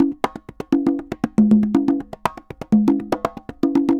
Congas_Salsa 120_7.wav